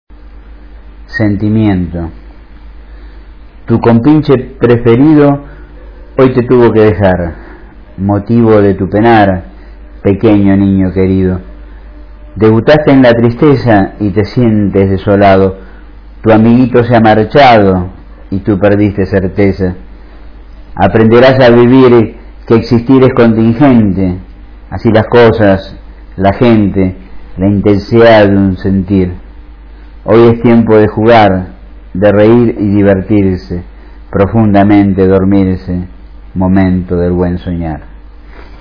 Recitado por el autor (0:38", 151 KB)